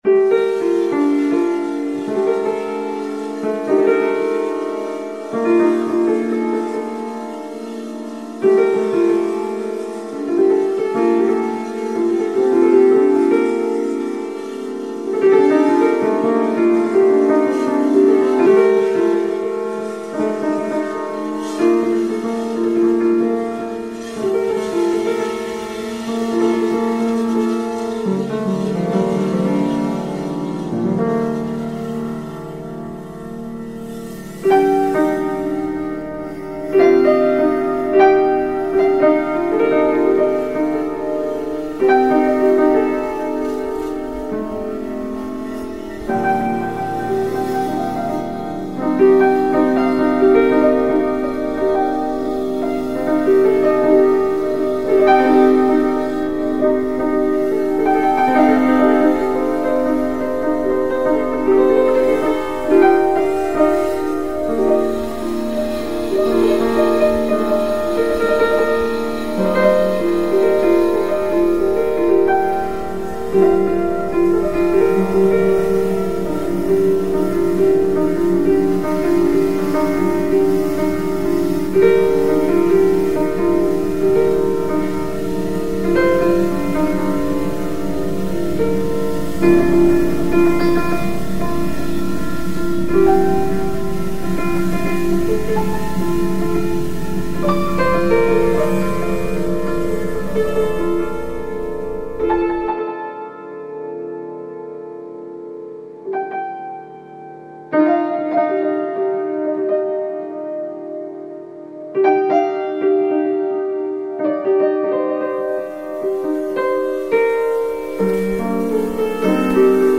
File under: Experimental